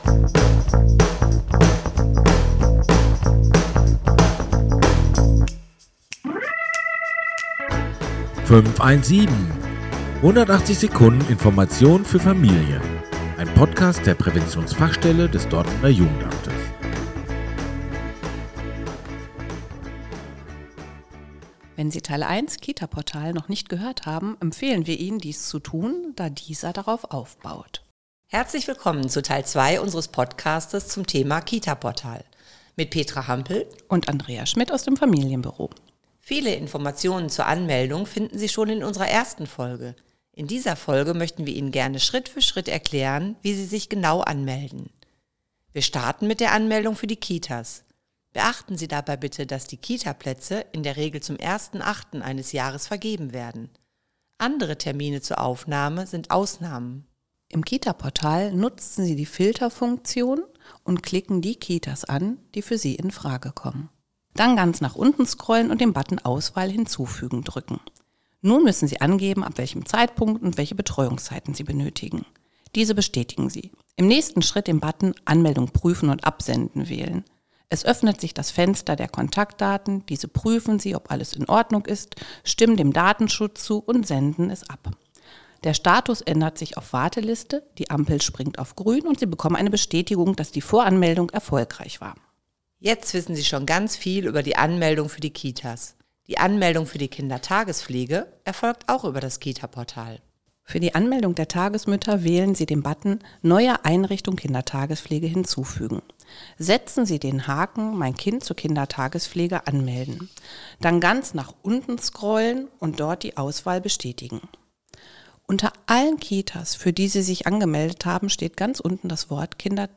In rund 180 Sekunden sind zu Themen wie der Willkommensbesuch, die Arbeit einer Hebamme oder Mehrsprachigkeit interessante Menschen zu Gast, die im Gespräch mit Fachkräften der Präventionsfachstelle Einblicke in ihre Arbeit bzw. ihre Themen geben.